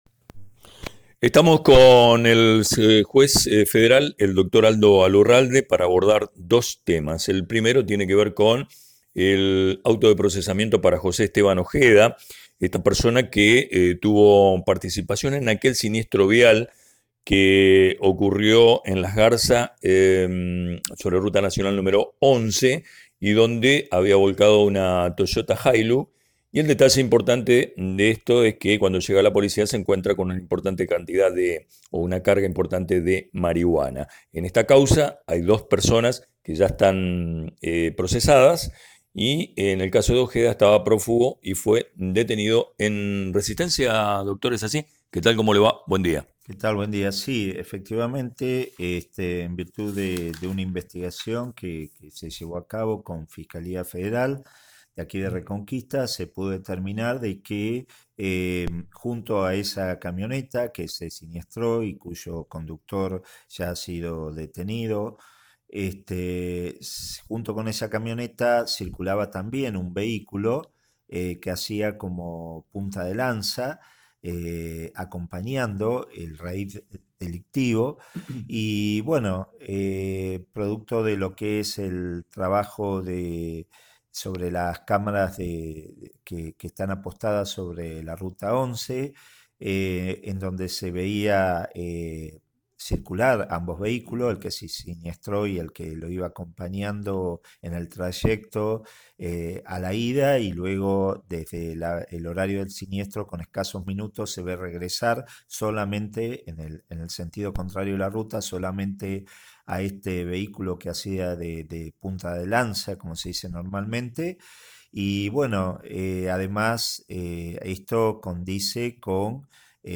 Entrevista-al-juez-federal-Alurralde-online-audio-converter.com_.ogg